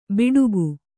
♪ biḍugu